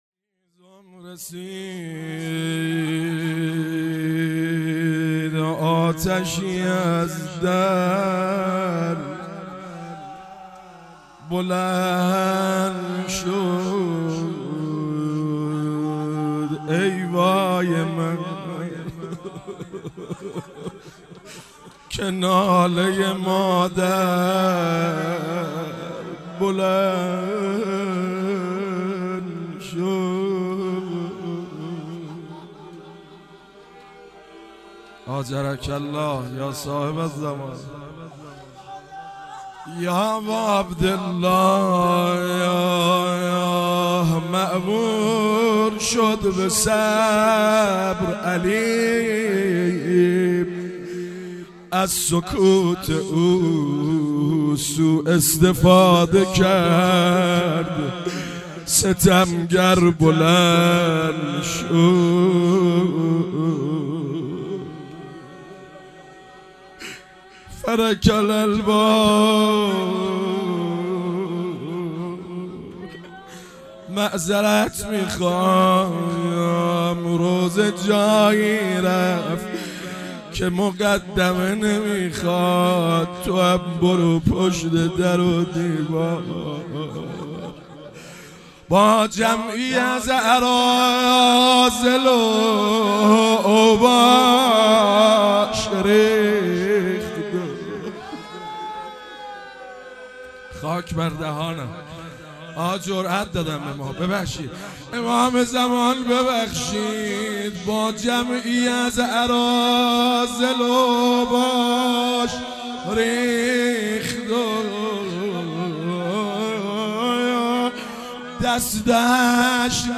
شهادت حضرت محسن علیه السلام 96 - روضه